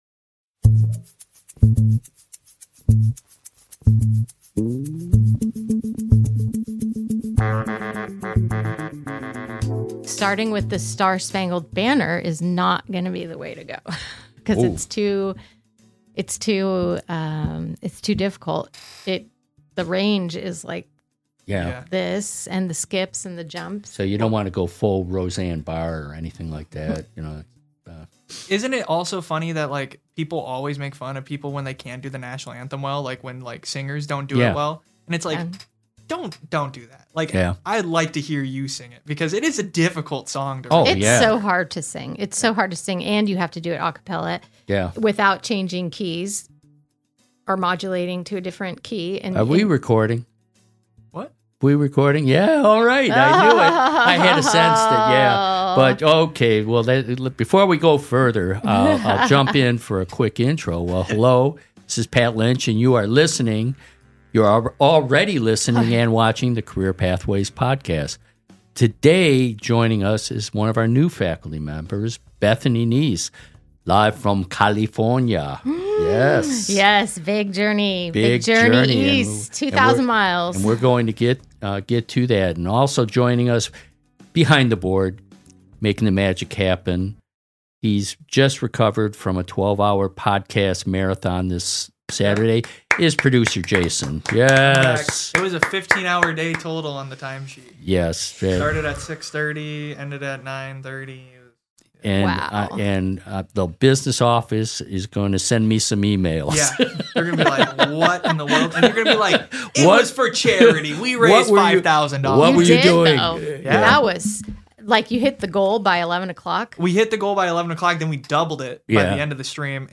[54] Can You Hear the Music? | Interview